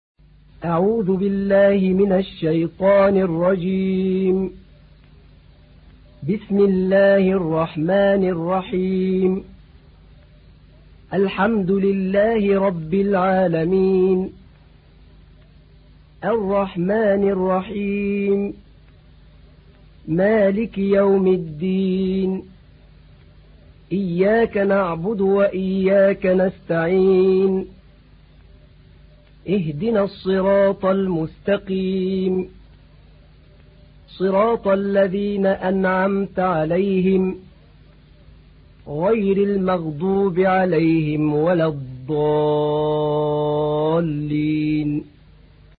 تحميل : 1. سورة الفاتحة / القارئ أحمد نعينع / القرآن الكريم / موقع يا حسين